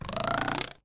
ruler_click_ripple_longer.ogg